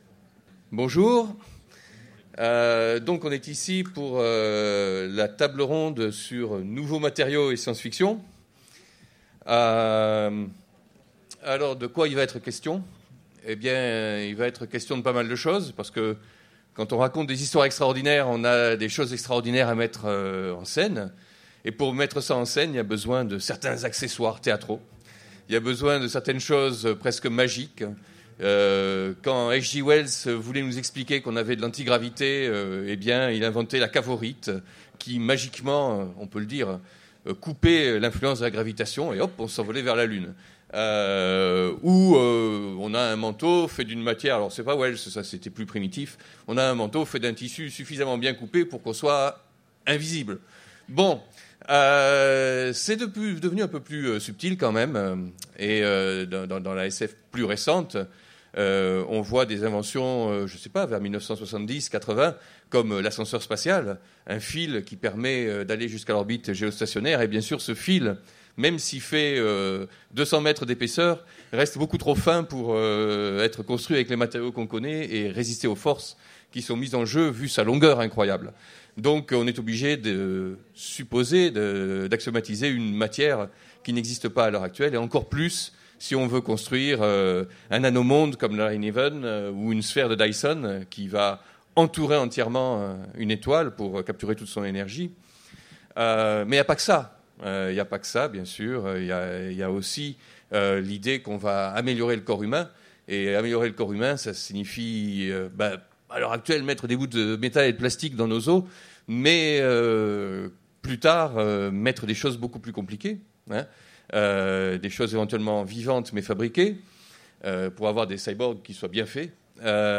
Utopiales 2015 : Conférence Matières à fiction !